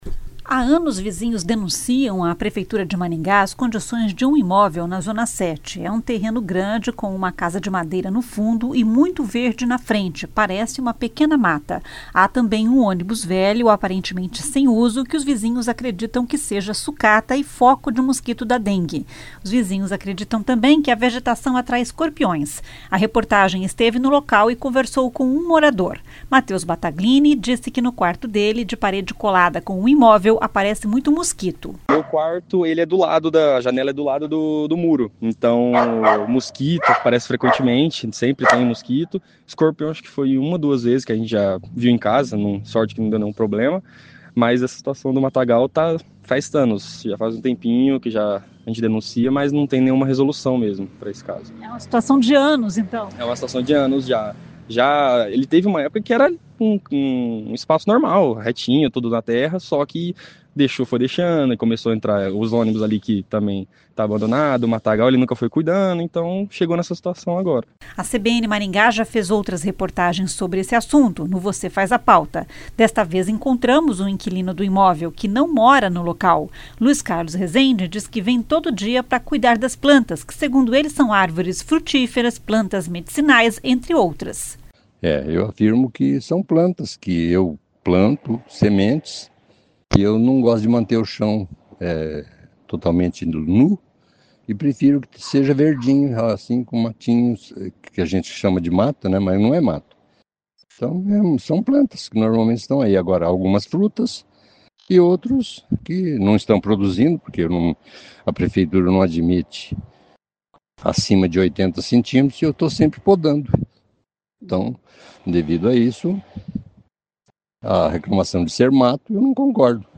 A reportagem esteve no local e conversou com um morador.